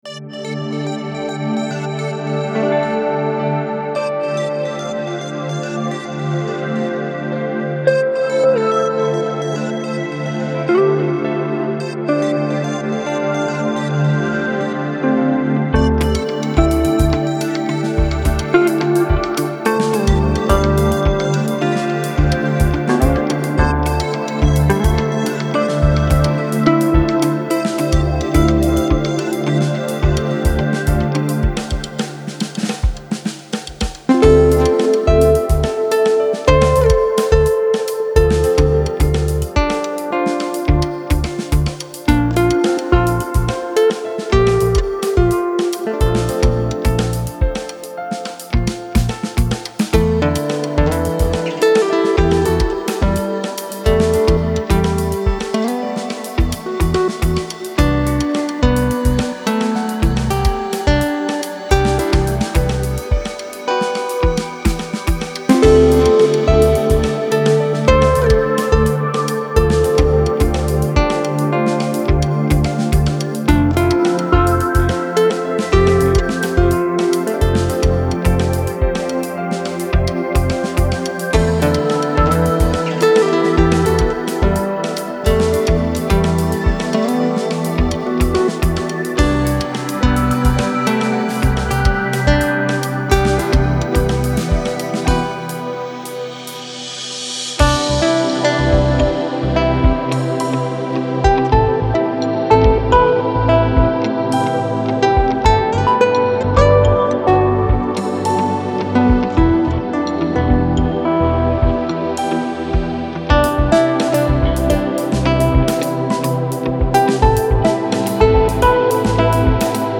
[DEMO]
Seven fourths (неприятные 7/4